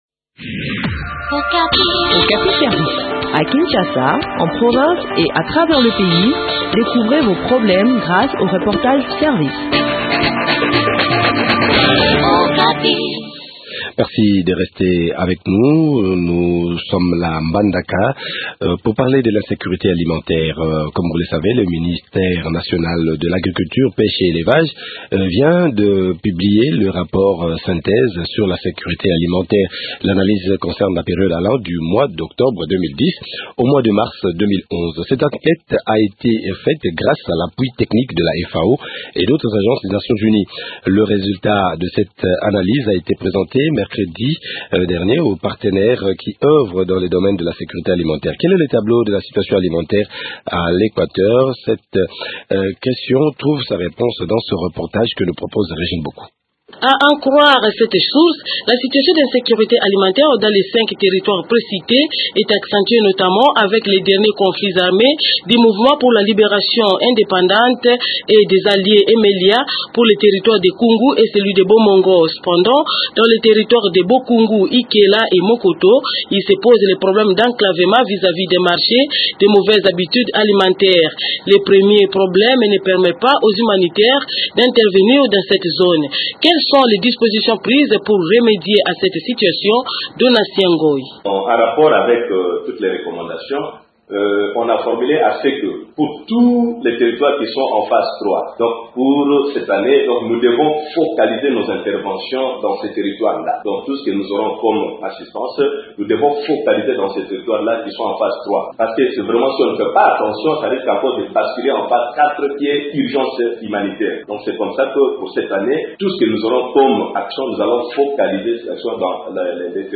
Retrouvez des précisions dans cet entretien